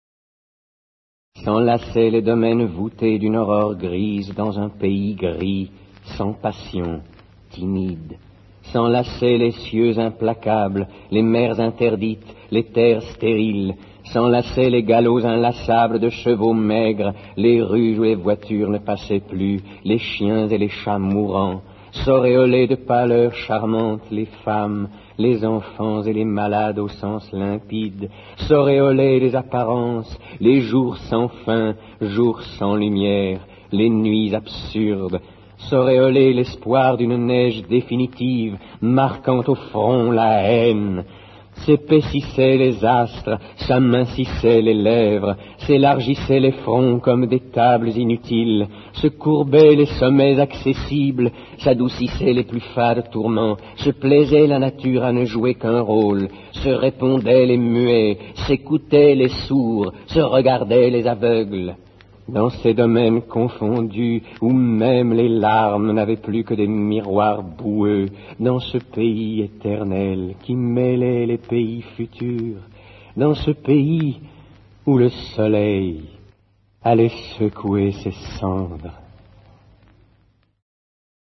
dit par Gérard PHILIPE